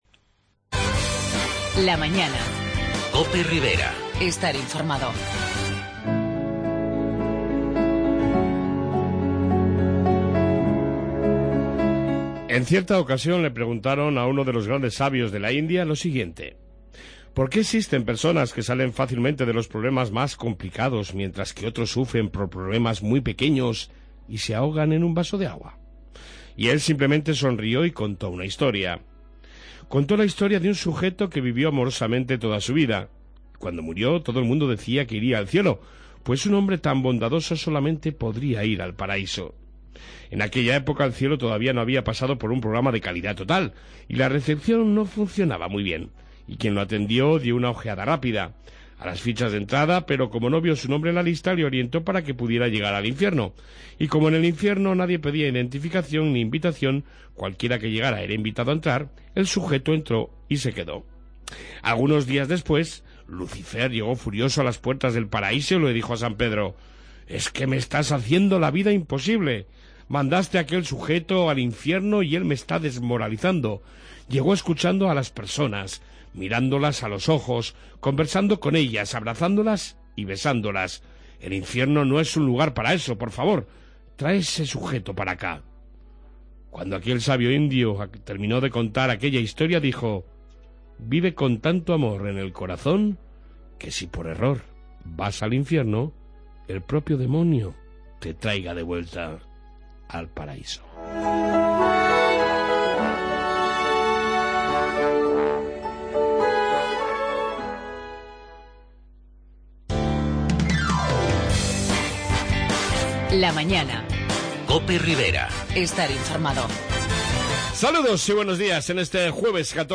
Entrevista sobre la Exposición de Proyecto Hombre